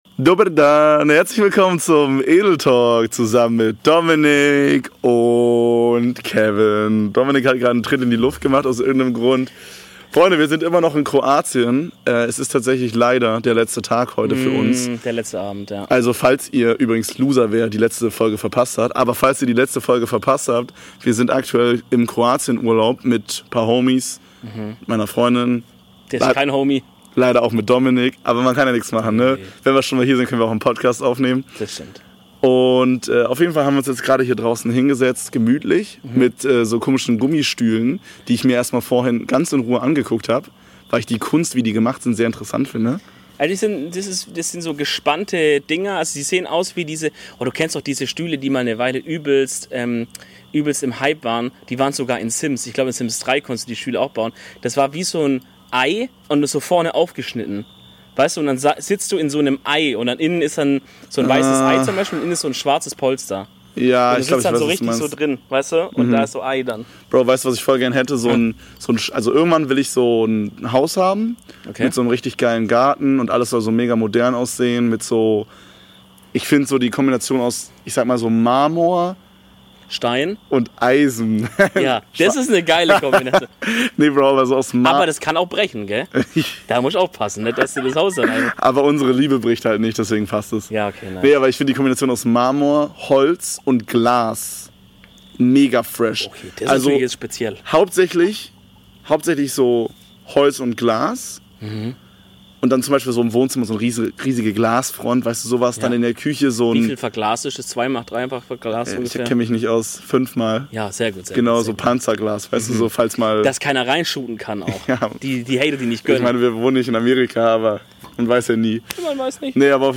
Die zweite Folge aus Kroatien besticht zusätzlich zum gewohnt guten Content dieses Mal auch mit Grillengezierpe im Hintergrund.